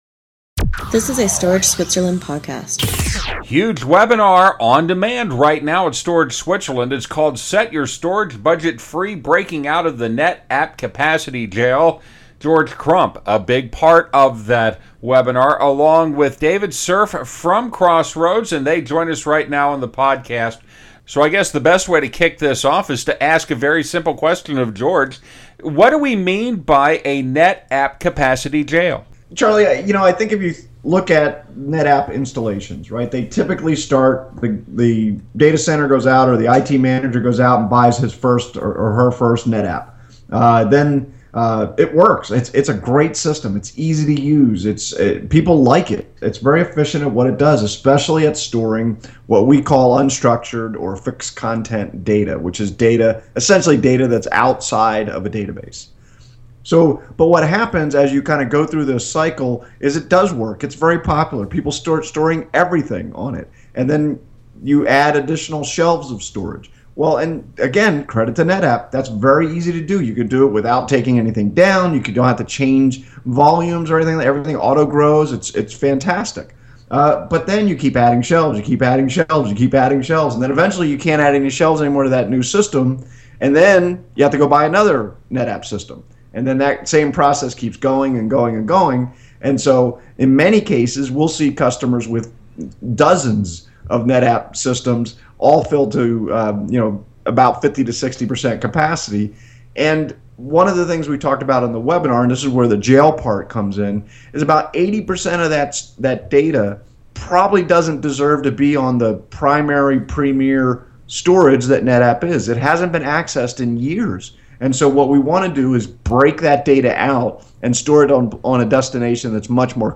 talk with me about how you can set your budget free by breaking out of the NetApp capacity jail.